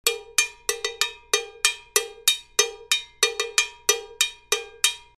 LP Mambo Cowbell
The LP Mambo Cowbell has a sharp, raised playing surface making it an easy bell to play in a timbale set up.
It's fairly dry unless you are hitting it with the shoulder of the stick really hard.